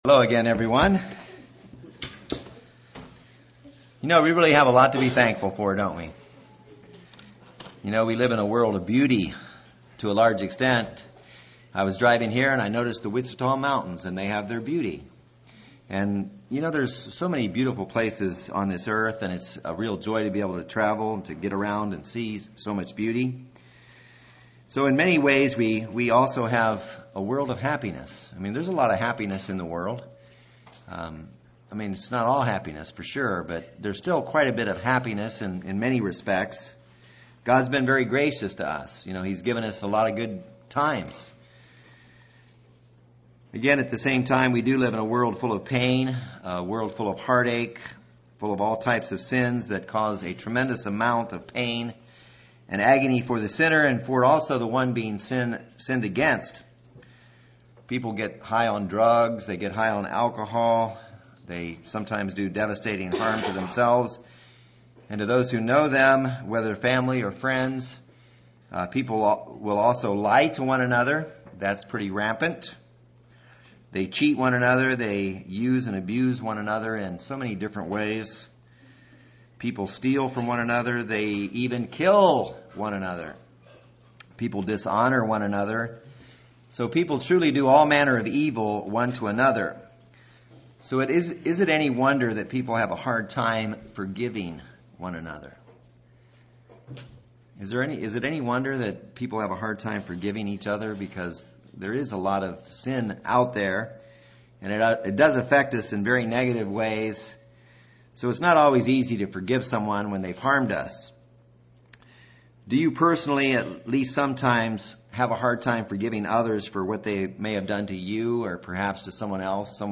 This sermon discusses the topic of forgiving others.